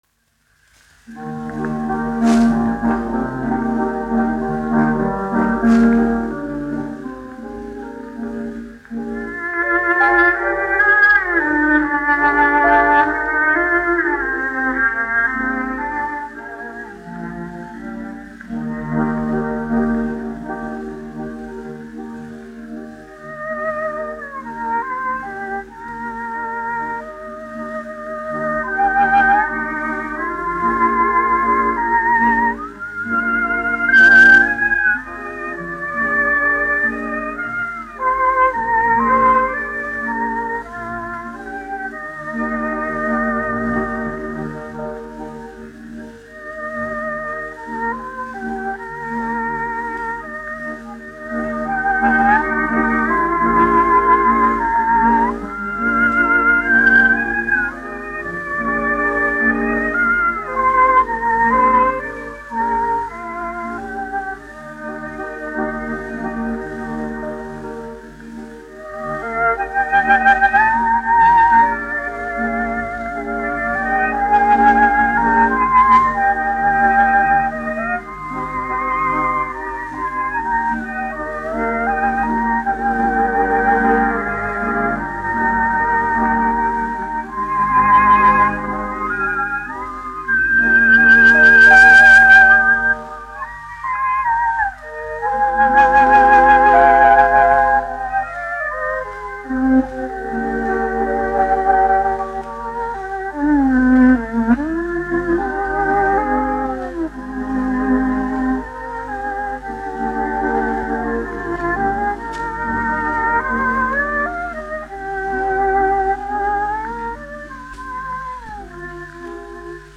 1 skpl. : analogs, 78 apgr/min, mono ; 25 cm
Orķestra mūzika, aranžējumi
Latvijas vēsturiskie šellaka skaņuplašu ieraksti (Kolekcija)